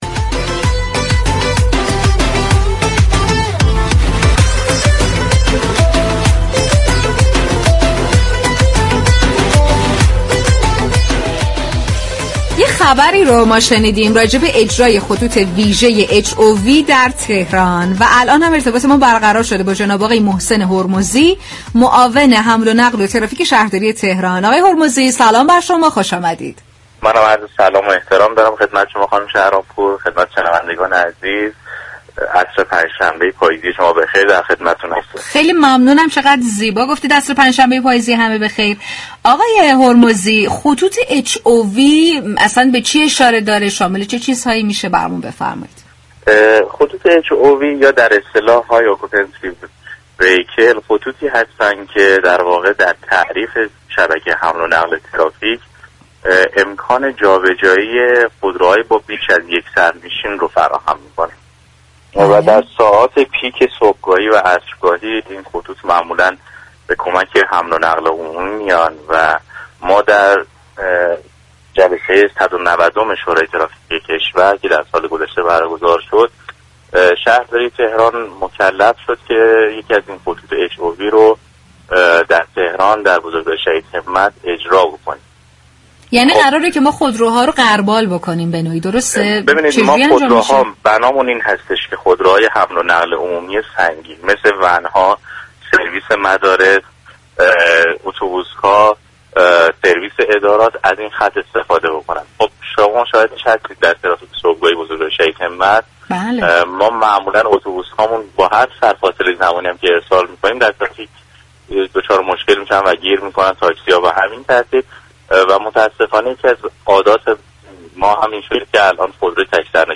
به گزارش پایگاه اطلاع رسانی رادیو تهران،محسن هرمزی معاون حمل و نقل و ترافیك شهرداری تهران در گفتگو با برنامه «اینجا تهران است» رادیو تهران در خصوص راه اندازی خطوط ویژه HOV (High Occupancy Vehicle) در تهران گفت: این خطوط، امكان جابجایی خودروهایی با بیش از یك سرنشین را فراهم می كنند.